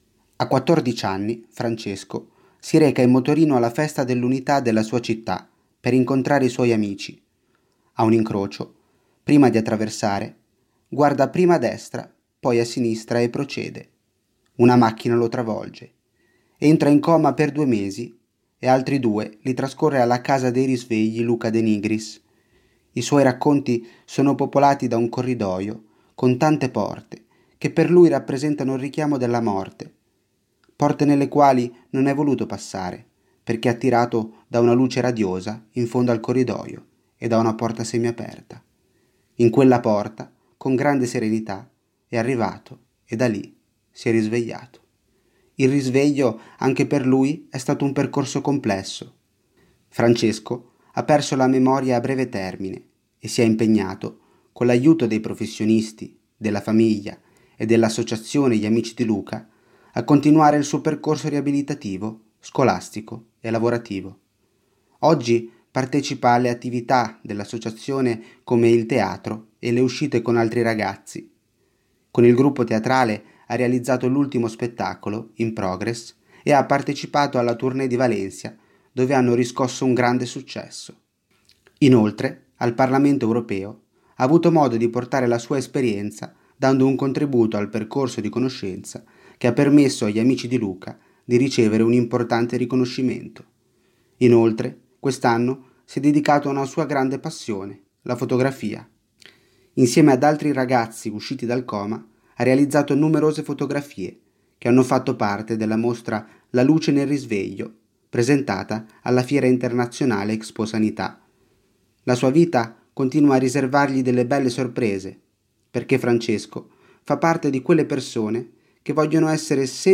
Storie > Audioletture